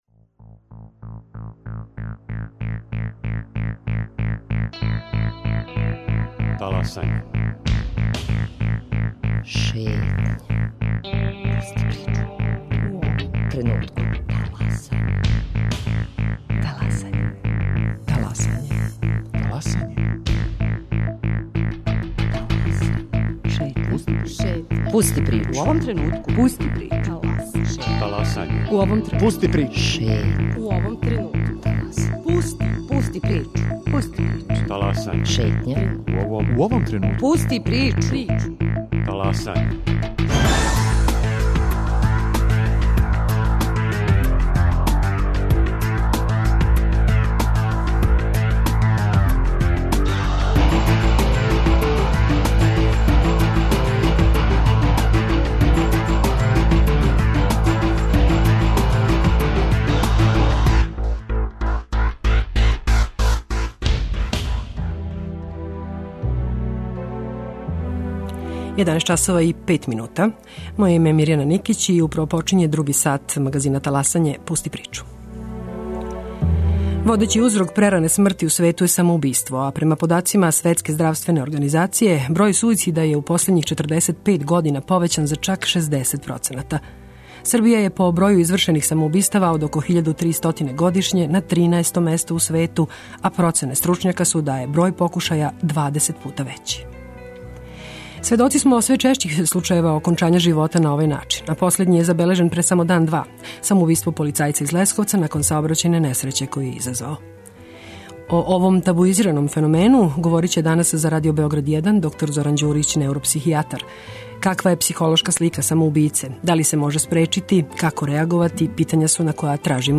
Разговараћемо и са стручњацима Центра за пружање емотивне подршке и превенцију самоубистава Срце из Војводине, одакле се и бележи највећи број суицида у Србији.